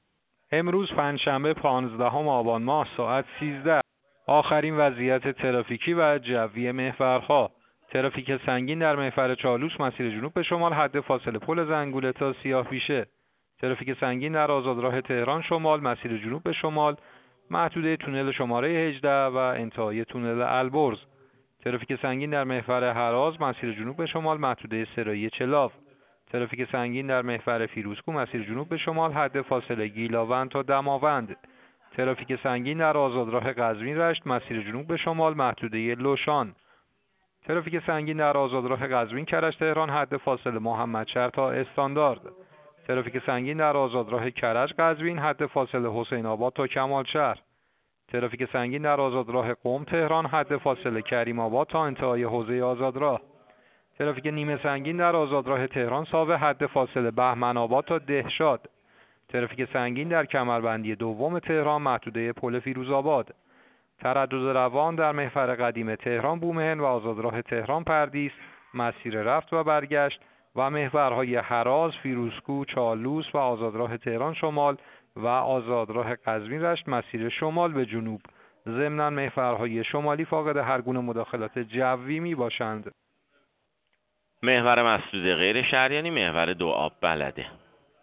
گزارش رادیو اینترنتی پایگاه‌ خبری از آخرین وضعیت آب‌وهوای شانزدهم آبان؛